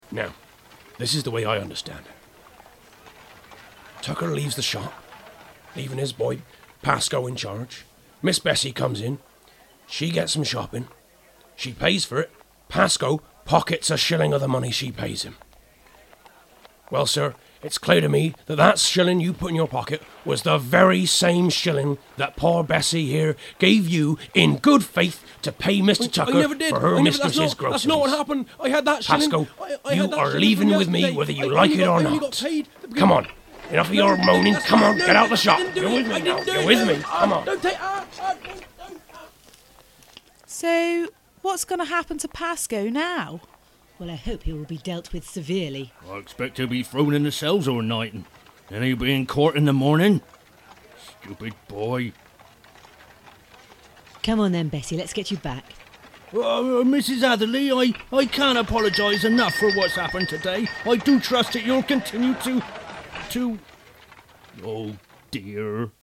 Stokes Croft Radio Play